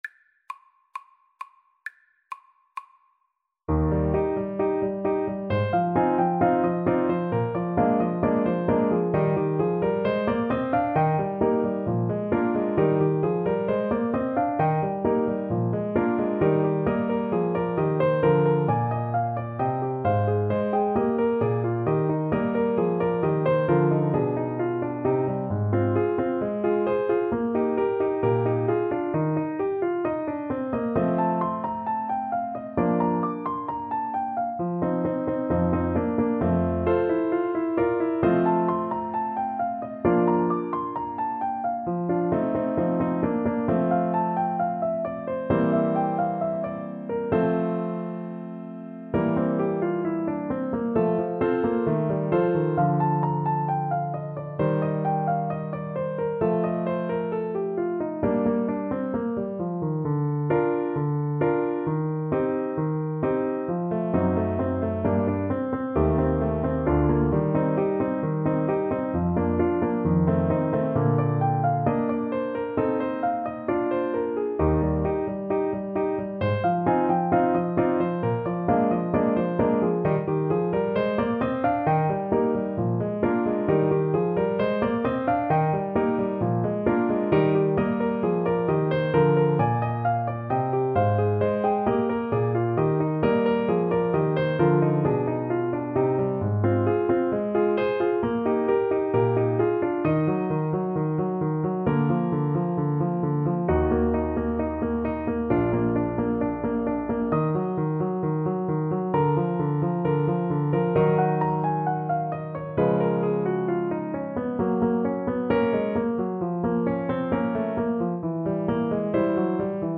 Play (or use space bar on your keyboard) Pause Music Playalong - Piano Accompaniment Playalong Band Accompaniment not yet available reset tempo print settings full screen
Eb major (Sounding Pitch) Bb major (French Horn in F) (View more Eb major Music for French Horn )
=132 Allegro assai (View more music marked Allegro)
Classical (View more Classical French Horn Music)